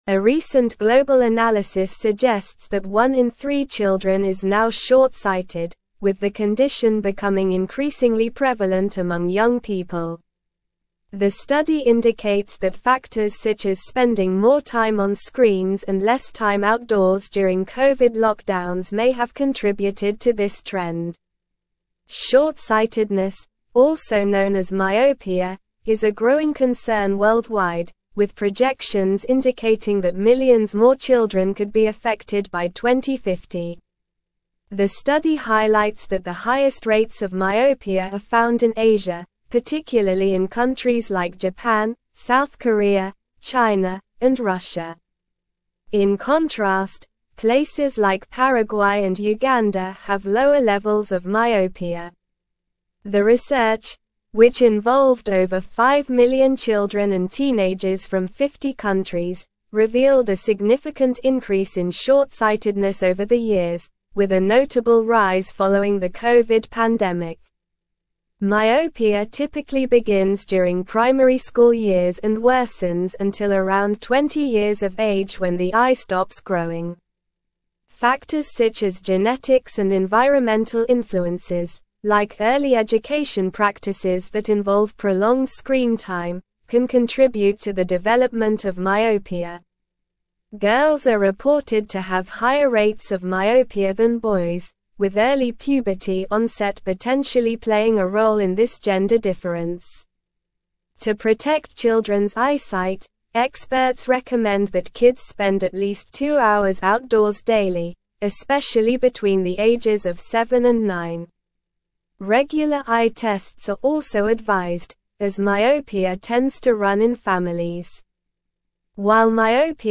Slow
English-Newsroom-Advanced-SLOW-Reading-Rising-Myopia-Rates-in-Children-Global-Concerns-and-Solutions.mp3